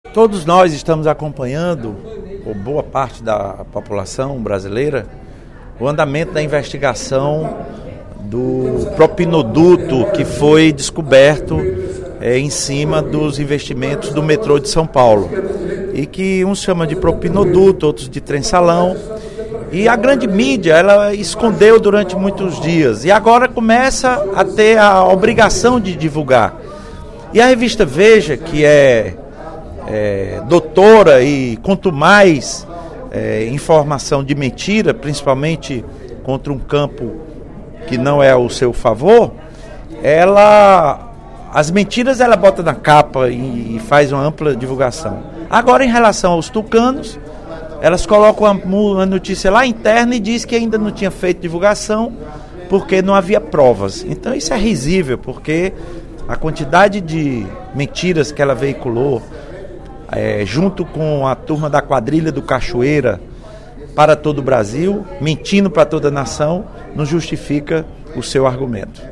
O deputado Professor Teodoro (PSD) parabenizou, nesta terça-feira (29/10), durante o primeiro expediente da sessão plenária, os servidores públicos pela semana comemorativa.